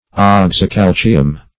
Search Result for " oxycalcium" : The Collaborative International Dictionary of English v.0.48: Oxycalcium \Ox`y*cal"ci*um\, a. [Oxy (a) + calcium.] Of or pertaining to oxygen and calcium; as, the oxycalcium light.
oxycalcium.mp3